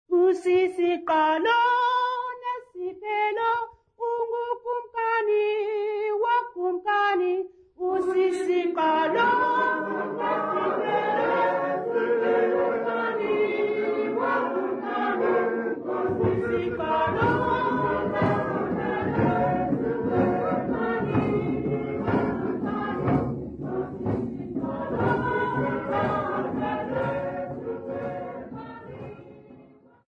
Xhosa festival workshop participants
Folk music
Sacred music
Field recordings
Africa South Africa Alice sa
Xhosa festival workshop performance accompanied by drum and rattle